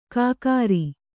♪ kākāri